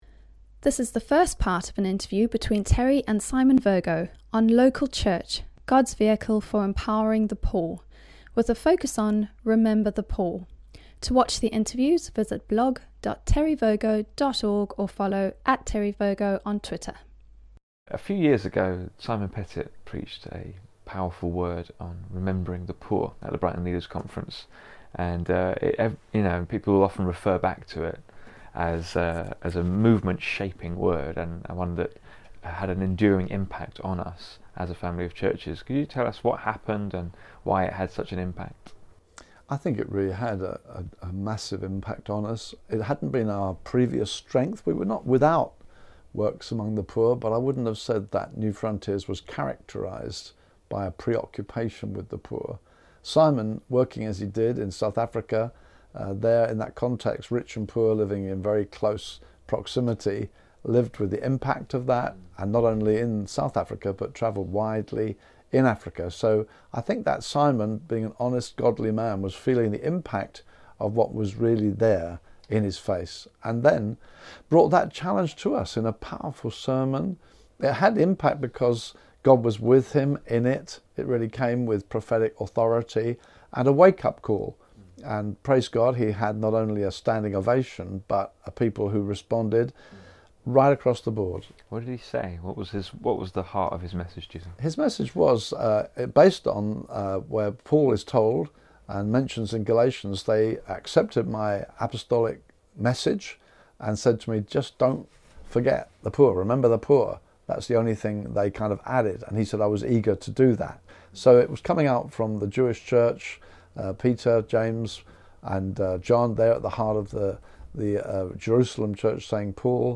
Interview+13a.+'Remember+the+poor'.mp3